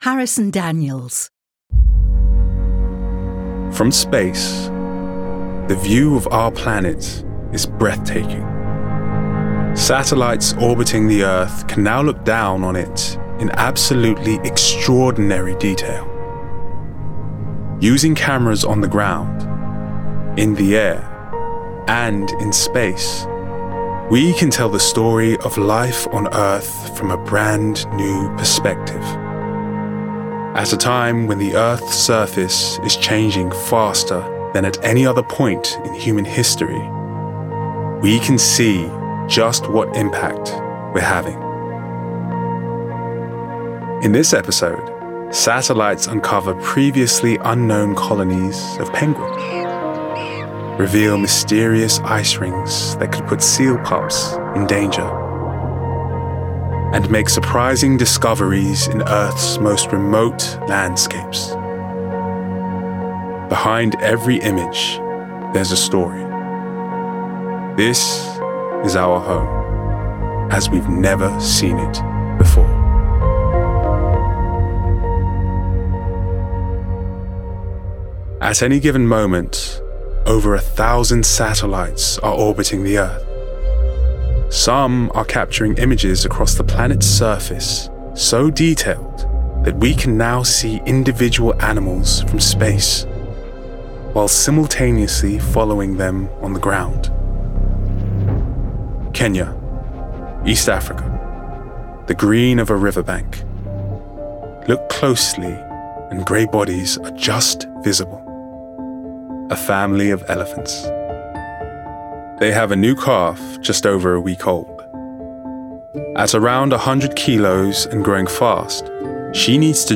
Description: London: effortless, gravitas, moving
Age range: 20s - 30s
Commercial 0:00 / 0:00
London*